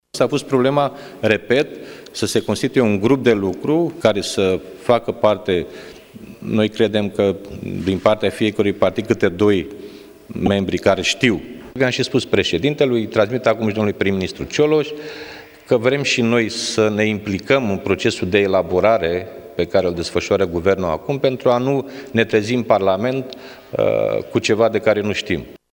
Președintele PSD, Liviu Dragnea: